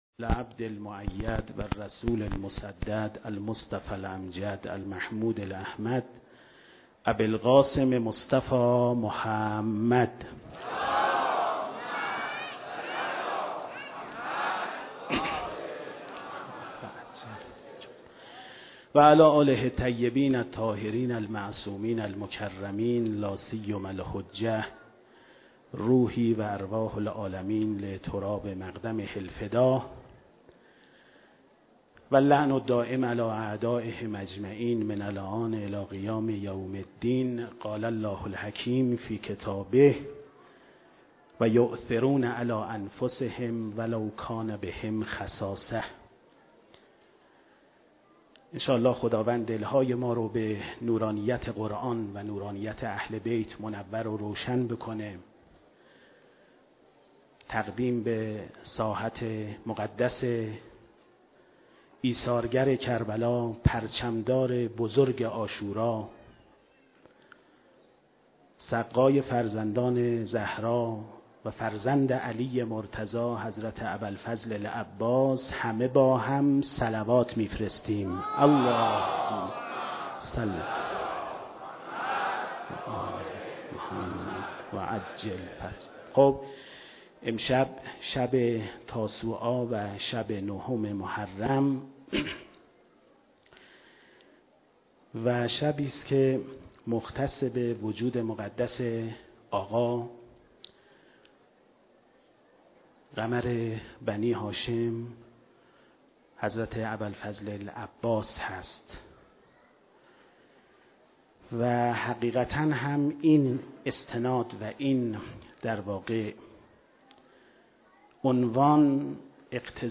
سخنرانی ذکر مصیبت